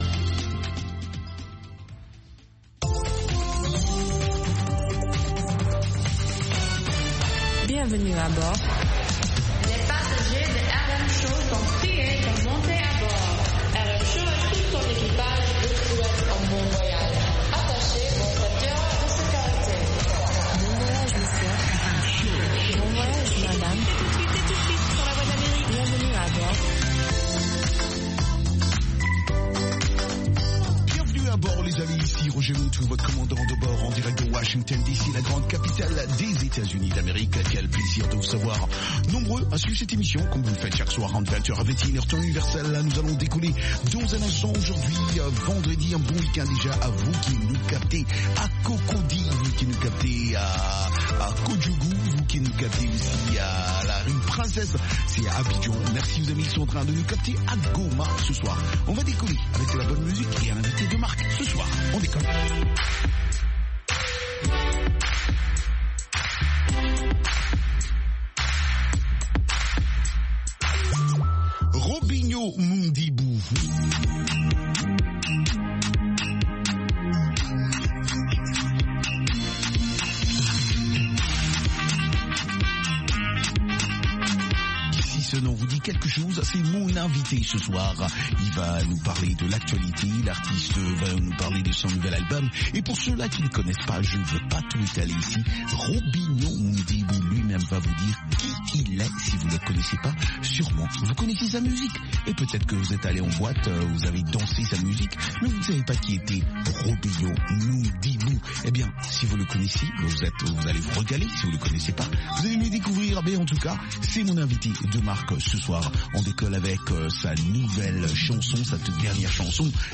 Ecoutez toute la musique des îles, Zouk, Reggae, Latino, Soca, Compas et Afro, et interviews de divers artistes